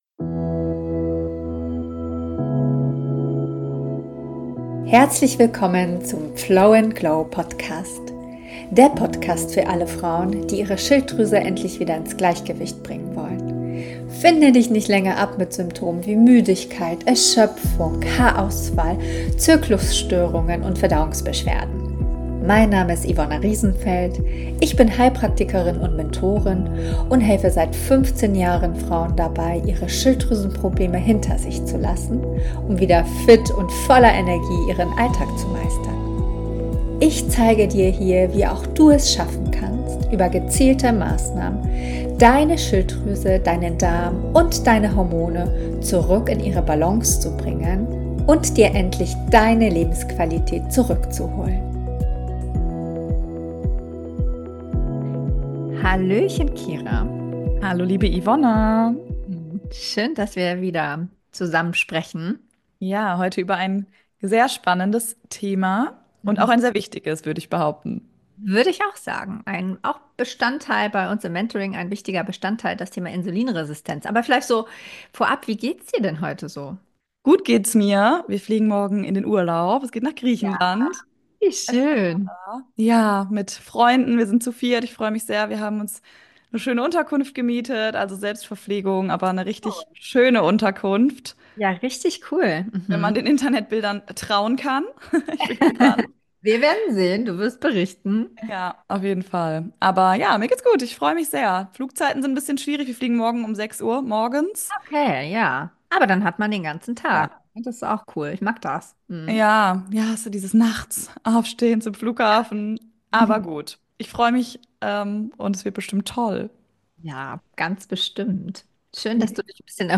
Ein ehrlicher, persönlicher und informativer Talk über Gesundheit, Verantwortung – und die Kraft, sich selbst ernst zu nehmen.